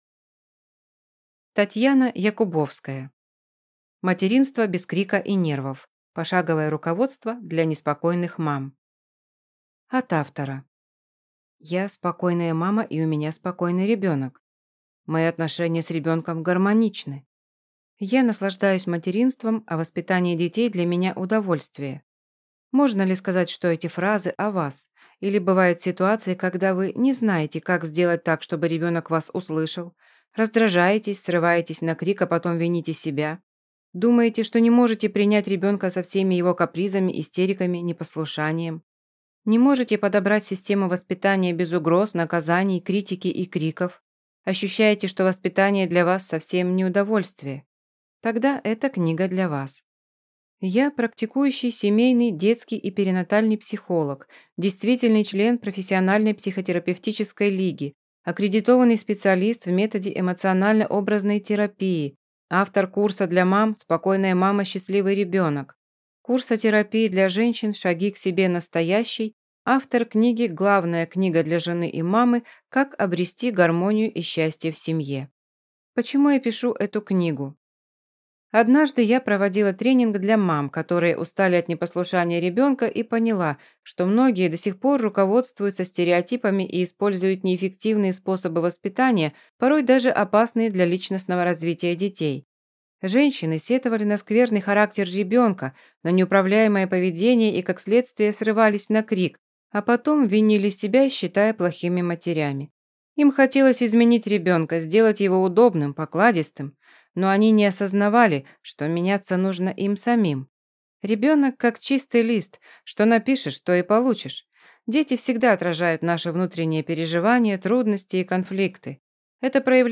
Аудиокнига Материнство без крика и нервов. Пошаговое руководство для неспокойных мам | Библиотека аудиокниг